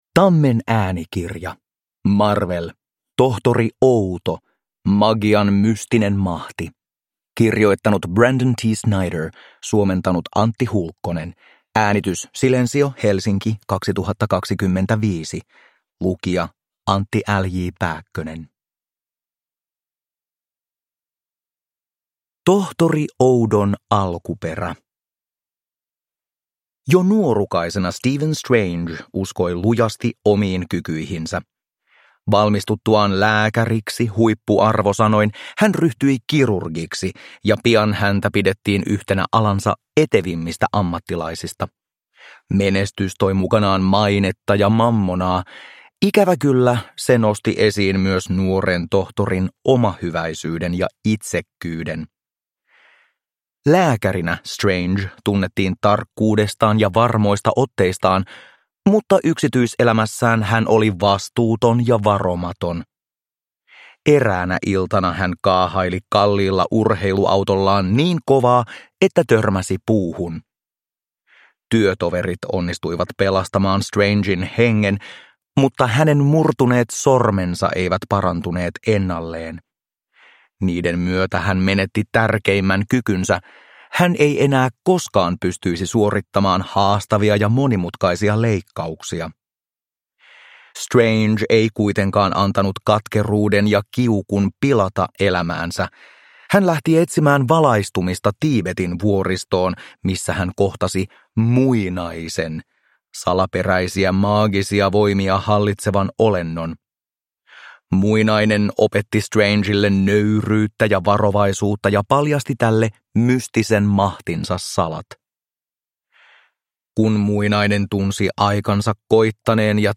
Marvel. Tohtori Outo: Magian mystinen mahti – Ljudbok